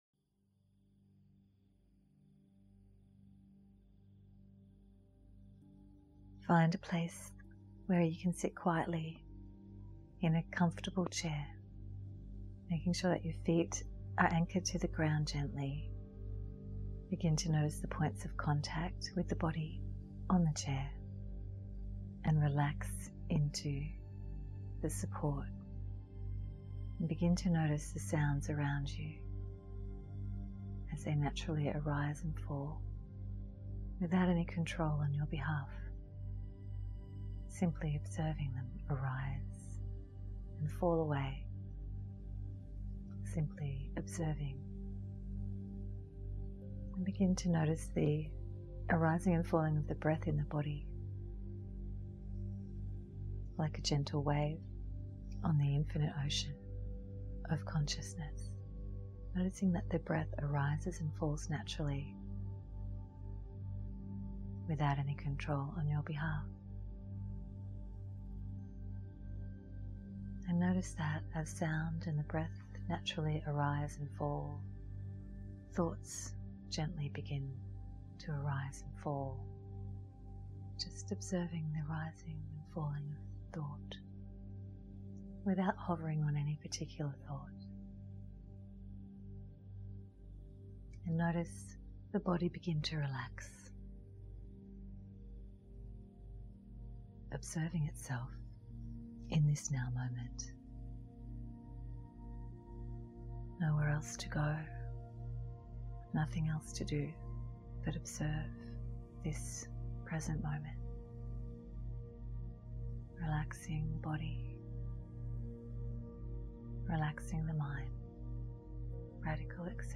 unified field meditation.mp3